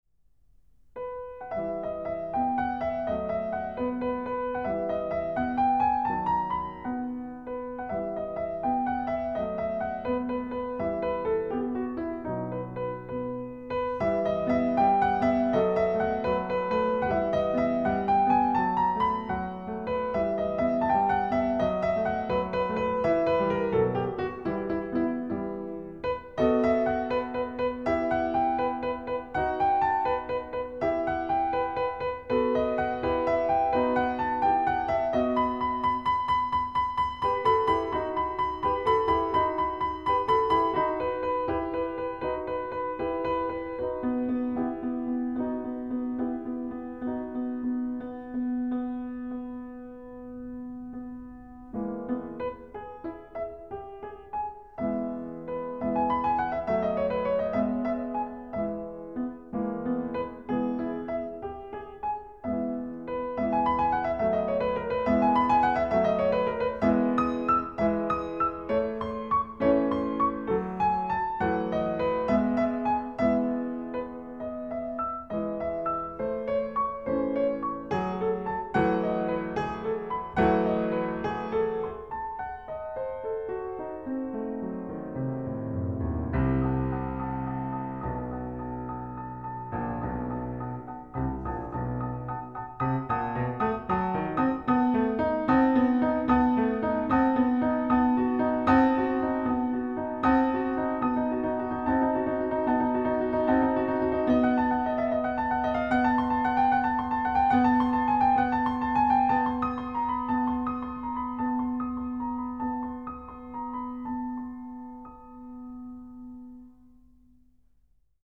piano